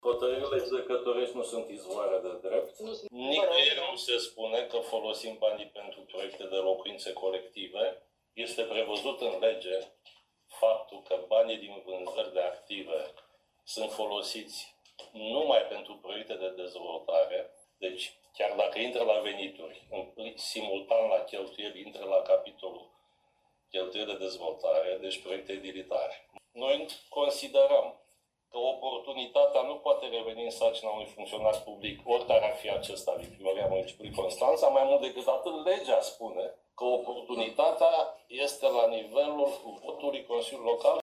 Ședință CLM.